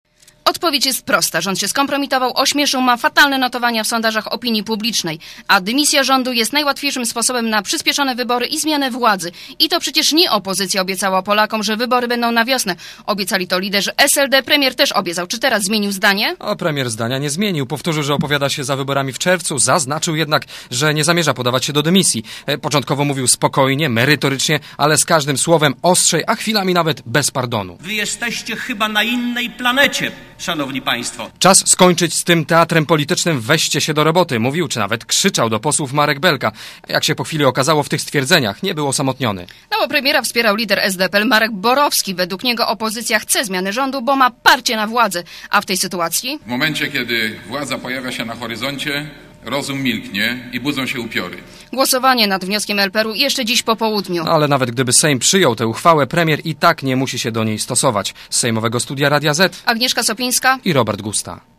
* Posłuchaj relacji reporterów Radia ZET z Sejmu*